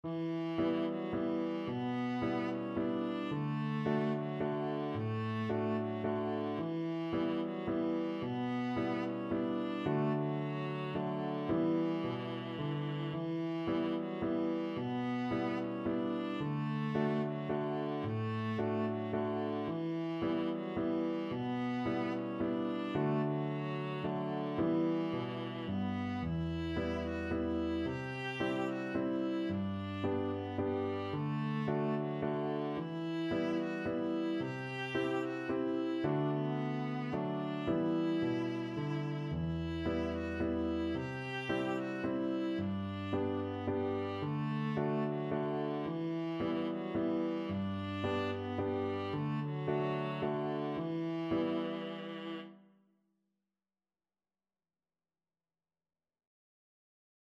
Viola
Traditional Music of unknown author.
3/4 (View more 3/4 Music)
= 120 Moderato =c.110
D4-G5
B minor (Sounding Pitch) (View more B minor Music for Viola )
Irish